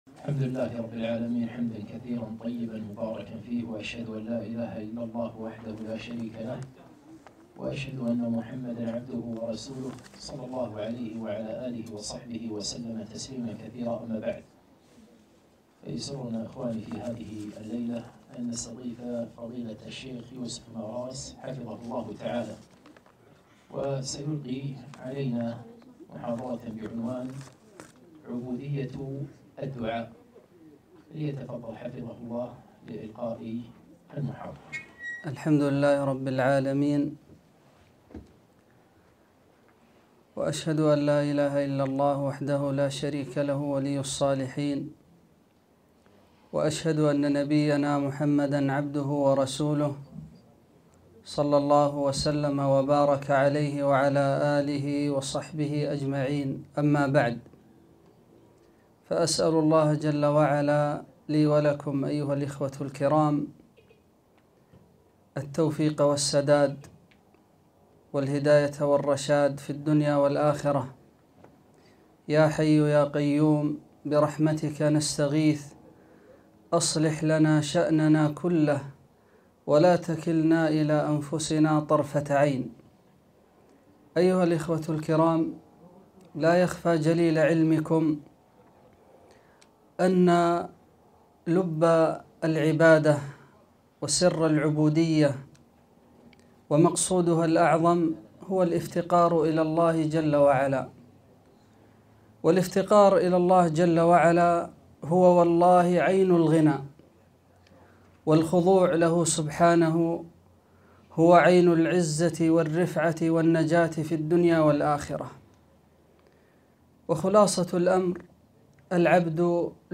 محاضرة - عبودية الدعاء